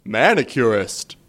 描述：男人说“美甲师”。
Tag: 卡通 语音 娱乐 美甲师 配音 搞笑